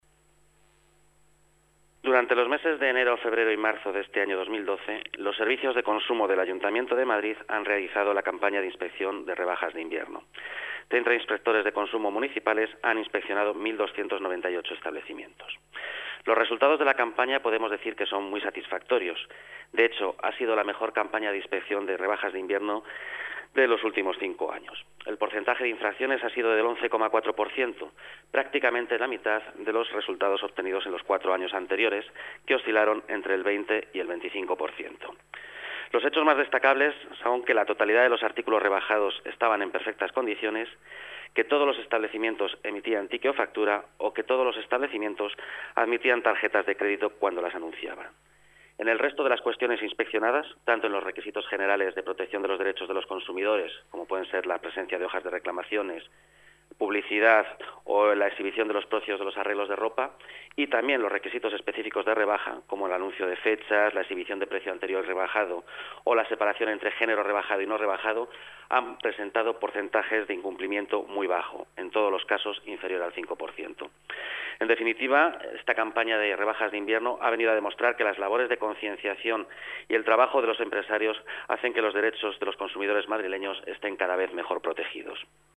Nueva ventana:Declaraciones de Ángel Sánchez, coordinador general de Consumo: campaña rebajas